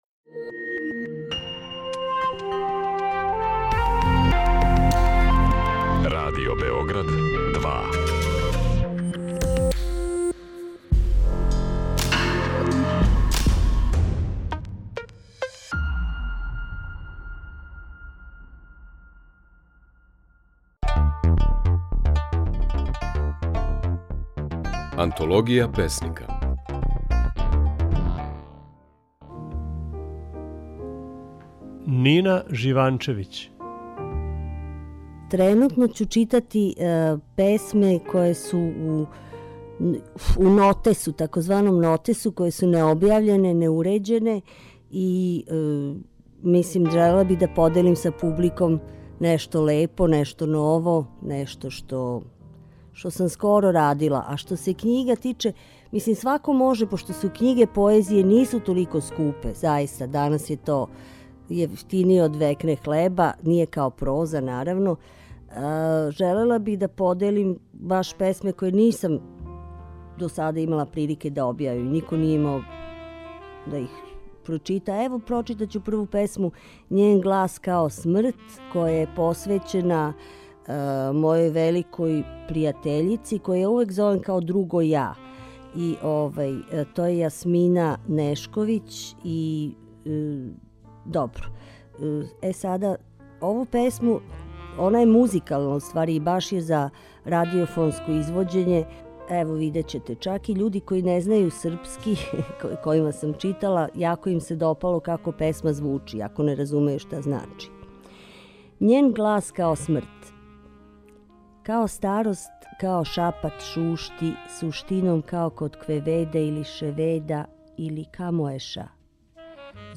Своје стихове говори песникиња Нина Живанчевић.